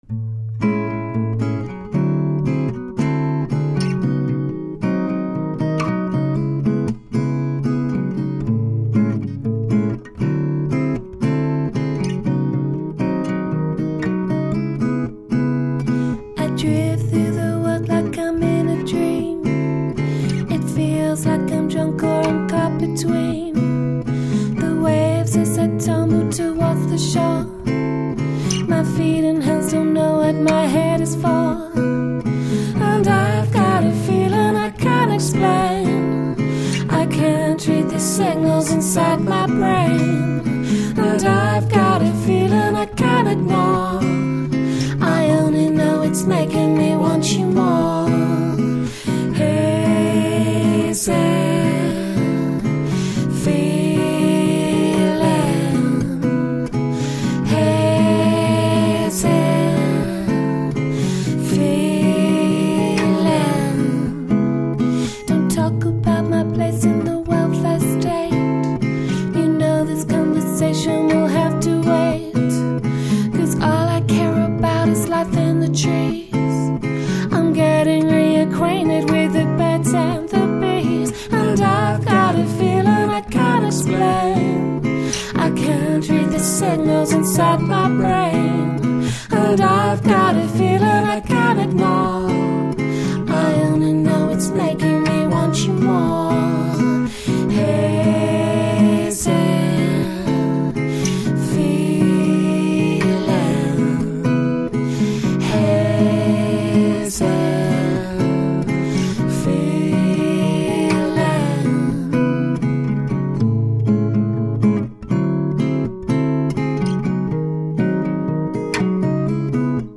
Folky guitars, jazzy melodies, sweet harmonies.